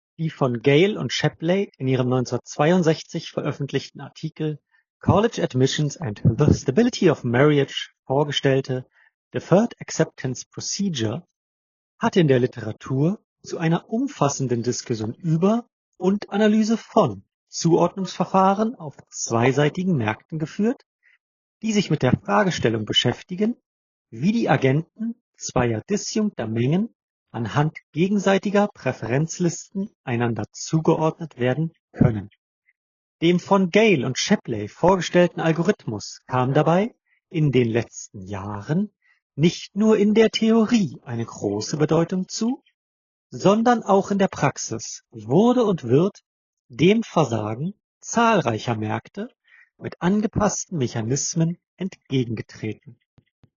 Sowohl über die Ohrhörer als auch das Super Mic ist die Geräuschunterdrückung der Umgebung hoch und von Straßenlärm und Vogelgezwitscher ist so gut wie nichts mehr zu hören. Über Super Mic klingt der Anrufer noch natürlicher und besser, wobei auch die Aufnahme über die Ohrhörer überzeugt.
Atmen ins Mikrofon des Ladecases ist mitunter ebenso zu hören wie das nur leichte Bewegen des Ladecases in der Hand.
Bei der Aufnahme wurde das Ladecase circa 10 cm vor dem Mund gehalten.
Nothing Ear (3) Super-Mic – Mikrofonqualität